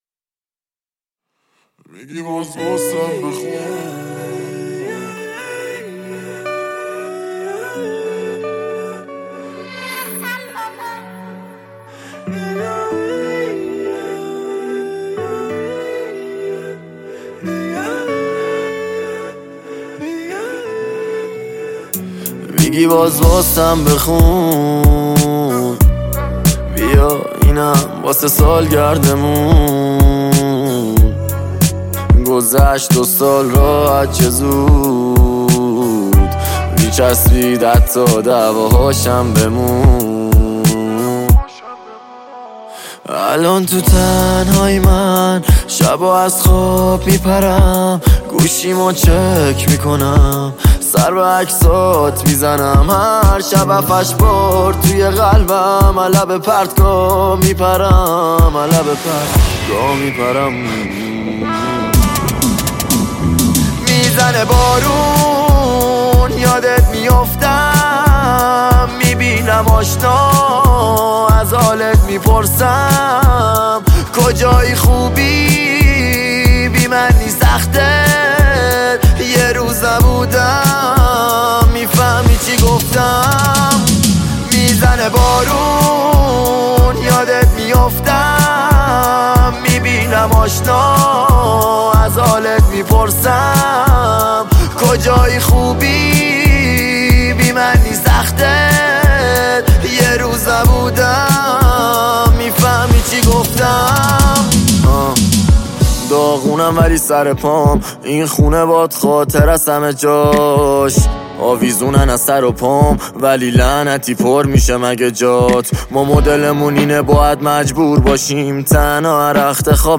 هیپ هاپ رپ